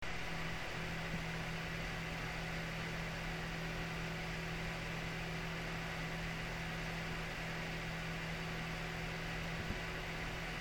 Aušinimo ir triukšmo rezultatai
NH-L9a-AM5 (2500 RPM @36,6 dBA)